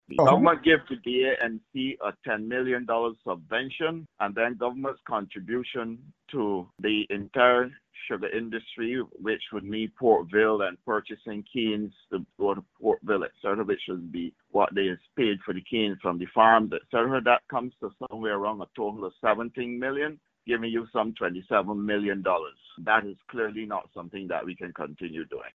Minister of Agriculture and Food Security, Indar Weir, tells Starcom Network News that government’s phased withdrawal from the sector commences in April, the start of the new financial year.